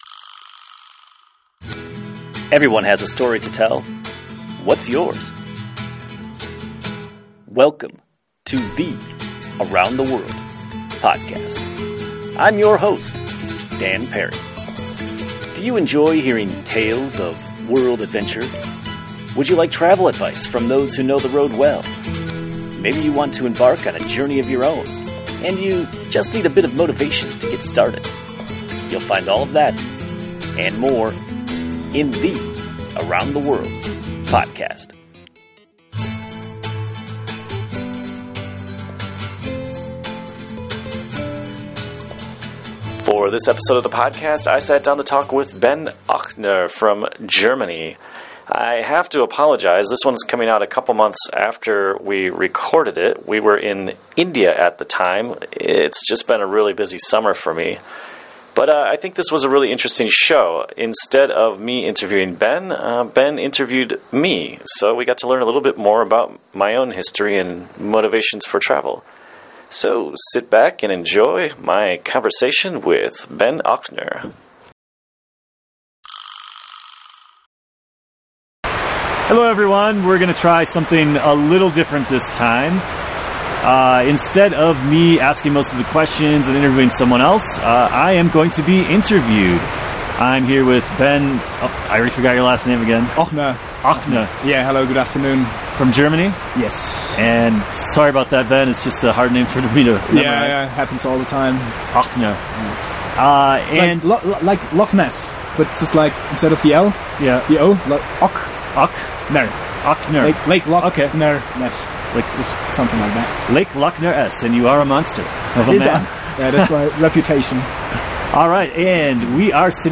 We were huddled in a tarp-covered shelter called a dhaba on a cold and rainy day in the remote outpost of Chhatru, India, population 120.